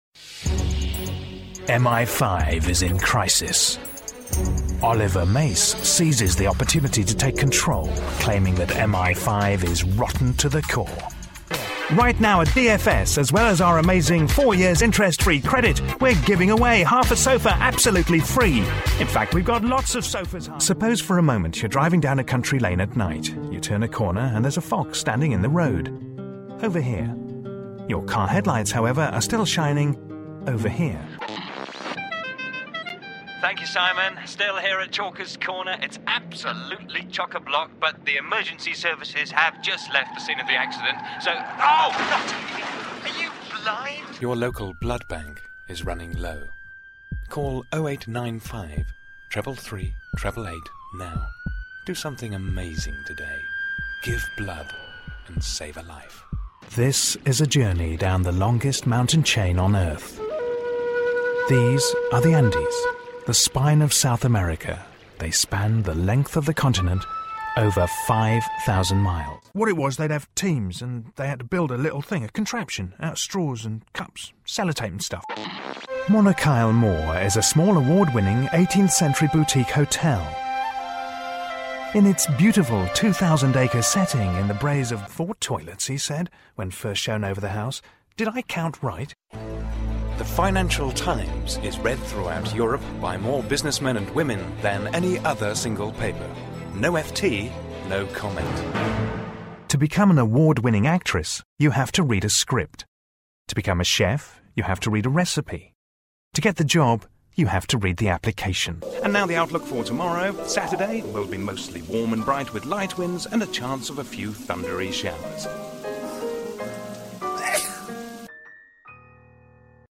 Essentially English voice with warm and intelligent delivery - documentaries, corporate narration and commercials.
britisch
Sprechprobe: Werbung (Muttersprache):